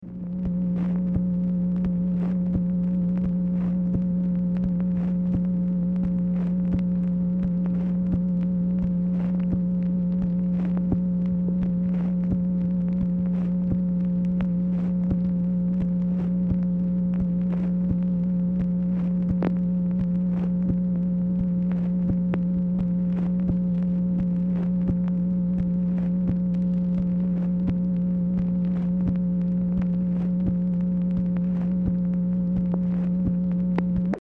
Telephone conversation # 10103, sound recording, MACHINE NOISE, 5/3/1966, time unknown | Discover LBJ
Format Dictation belt